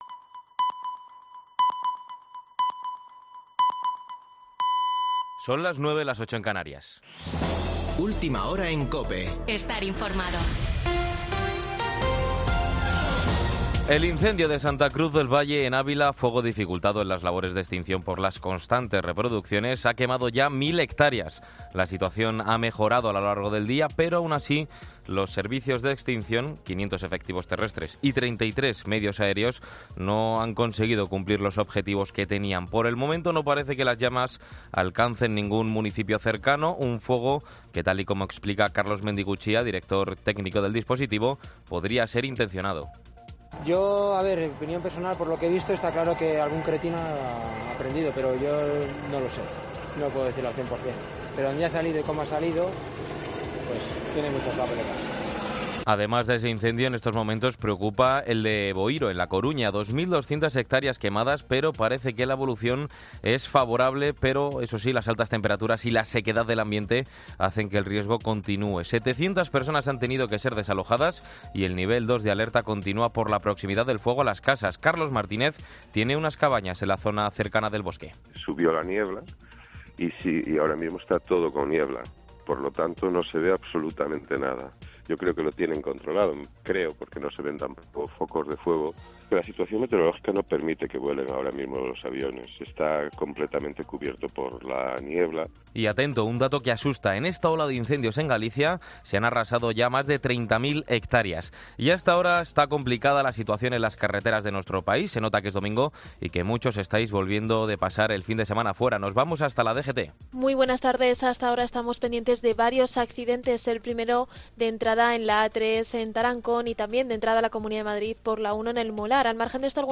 Boletín de noticias de COPE del 7 de agosto de 2022 a las 21.00 horas